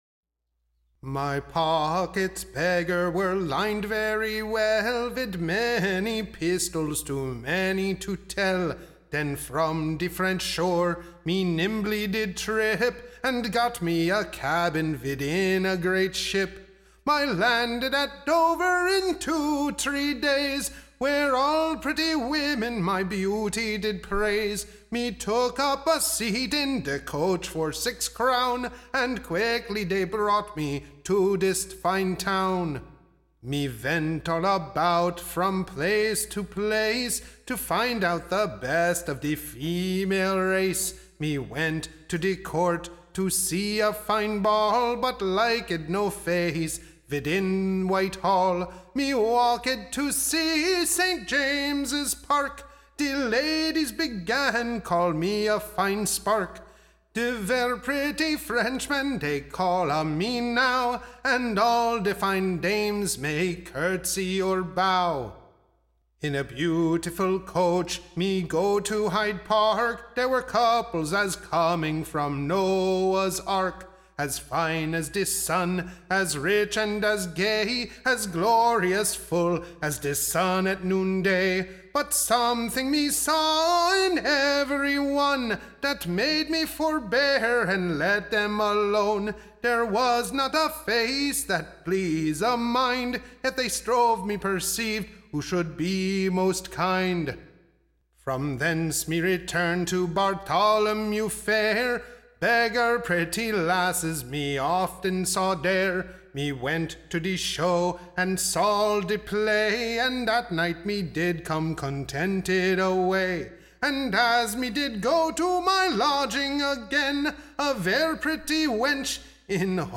Recording Information Ballad Title The Difficult French-Man's / Unsuccessful Adventers; / Or, A New Ballad of a Finical Monsieur, who came to Marry an English Lady, but could find none / for his purpose, till Conquer'd by a Crack.
Tune Imprint To the Tune of, There was a brisk Lass, &c. Standard Tune Title King James's Jig; The Country Farmer Media Listen 00 : 00 | 7 : 39 Download P3.136.mp3 (Right click, Save As)